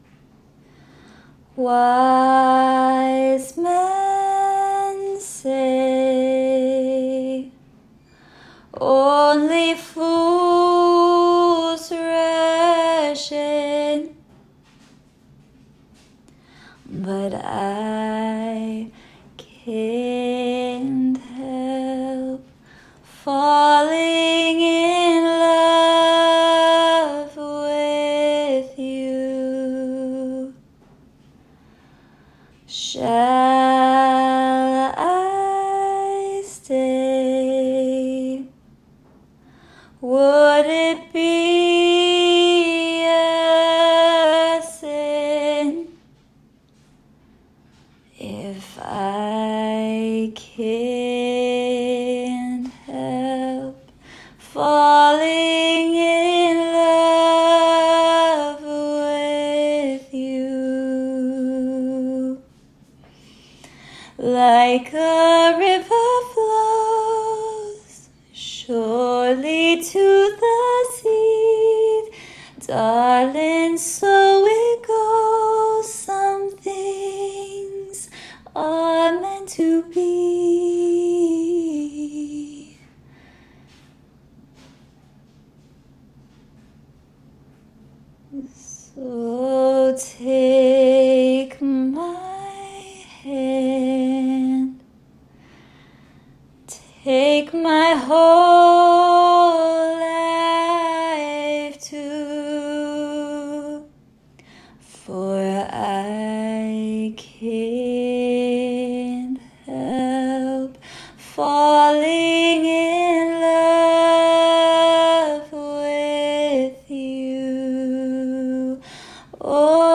AI pitch correction that auto-tunes any voice.
Falling back to chromatic correction.